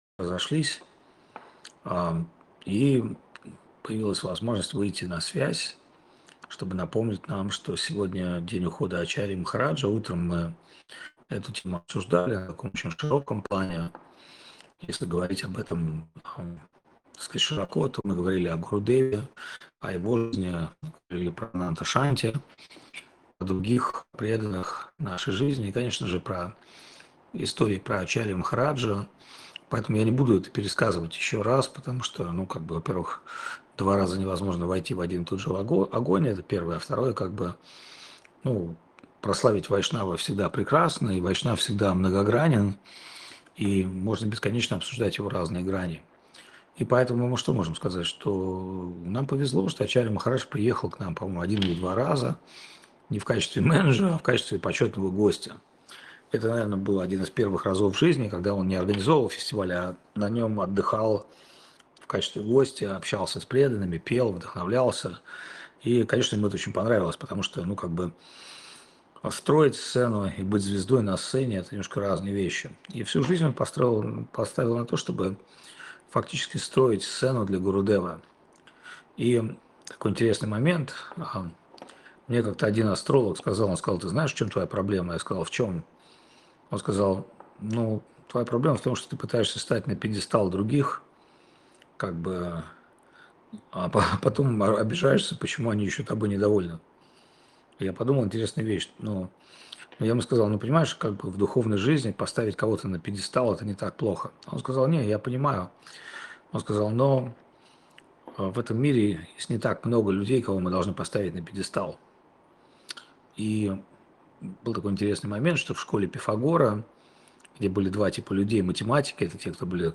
Место: Чиангмай (1138) Таиланд
Лекции полностью